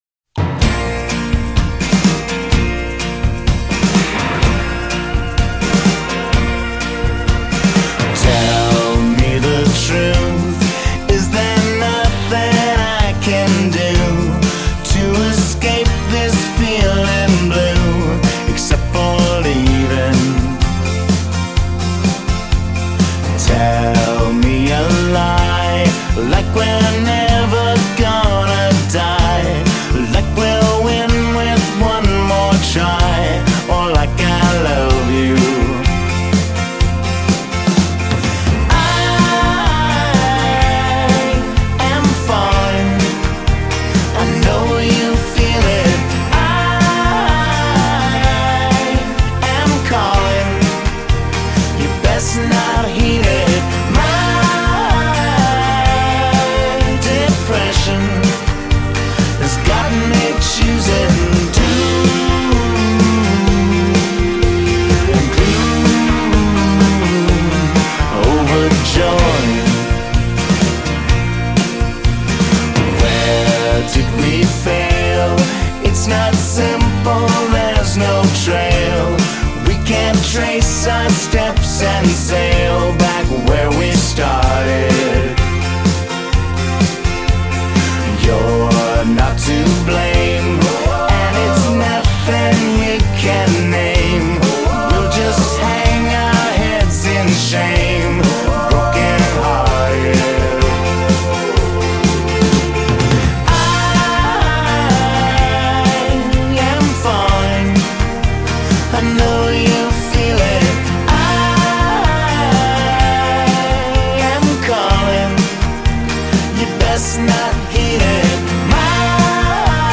pop’s most skilled purveyor of “dark bubblegum”
snappiest song about depression